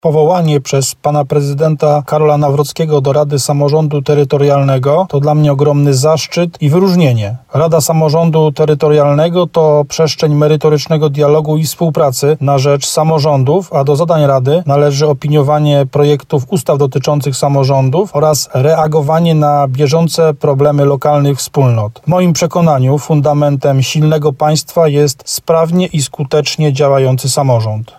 Jak mówił w rozmowie z RDN Małopolska, to duże wyróżnienie, które pozwoli na współpracę w tworzeniu prawa dotyczącego samorządów.